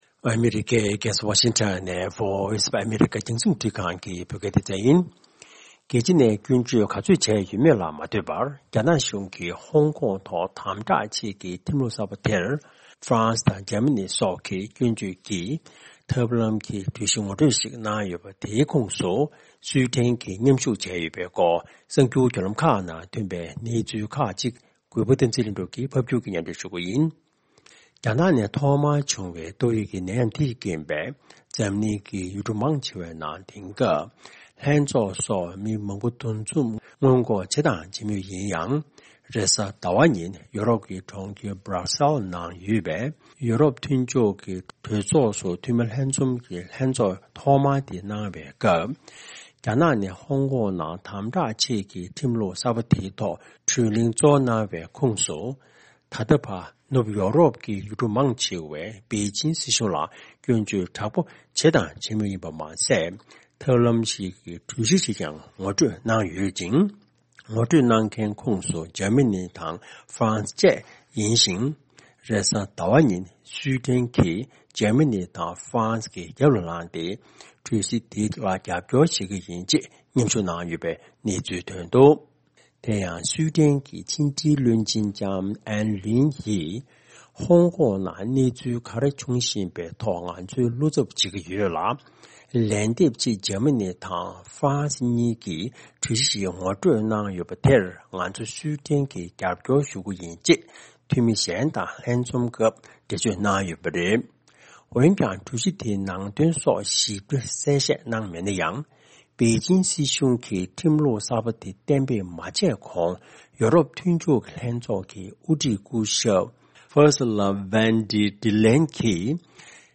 ཕབ་སྒྱུར་དང་སྙན་སྒྲོན་ཞུས་གནང་གི་རེད།།